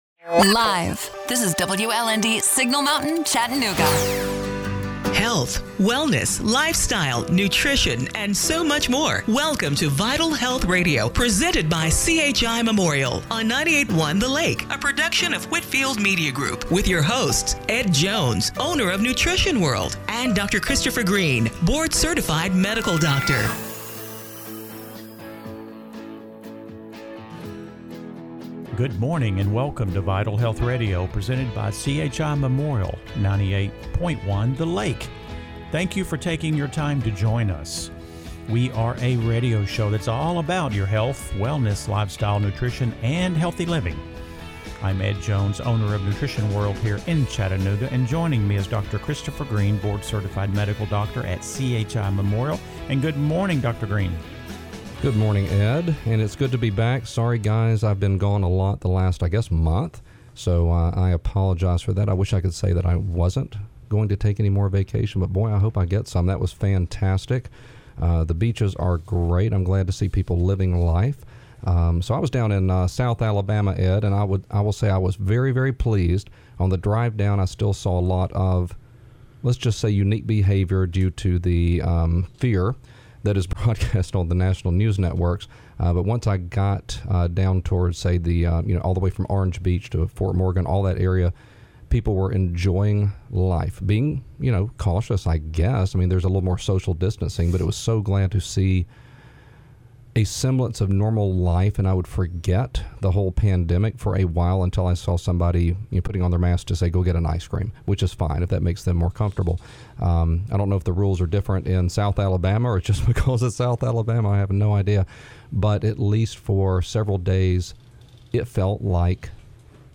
September 6, 2020 – Radio Show - Vital Health Radio